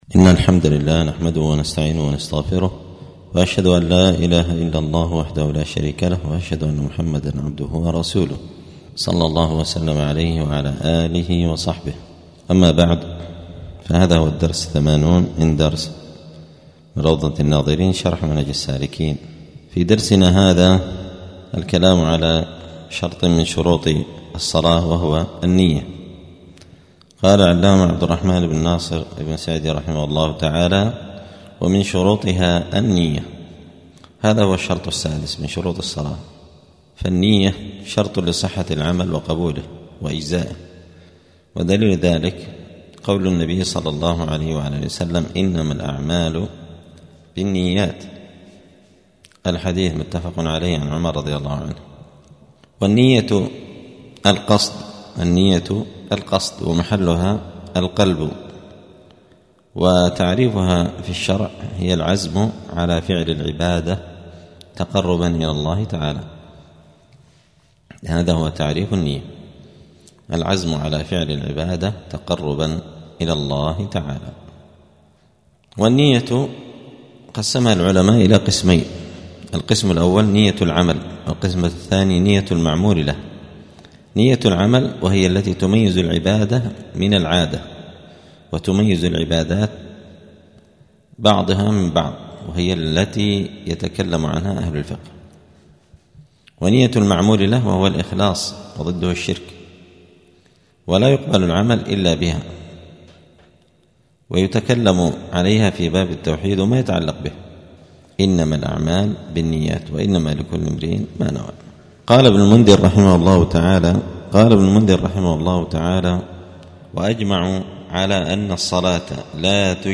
*الدرس الثمانون (80) {كتاب الصلاة باب شروط الصلاة النية}*
دار الحديث السلفية بمسجد الفرقان قشن المهرة اليمن